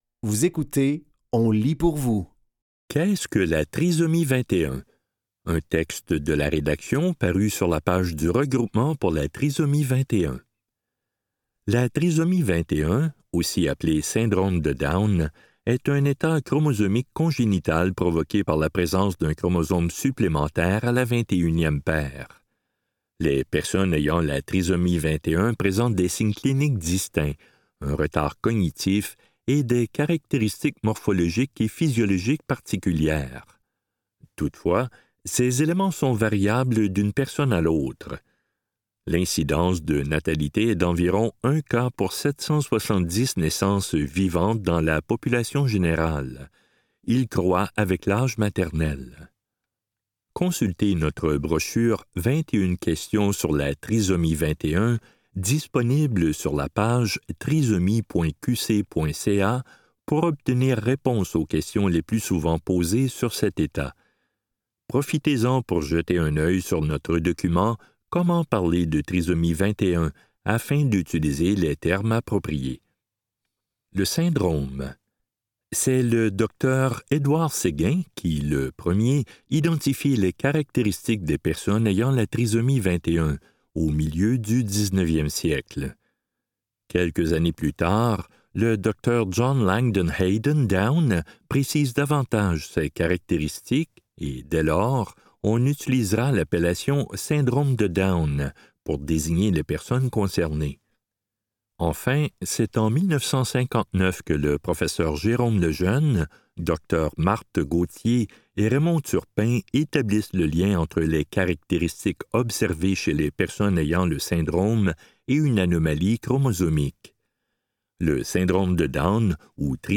Dans cet épisode de On lit pour vous, nous vous offrons une sélection de textes tirés des médias suivants : RT21, Le Devoir et La Presse.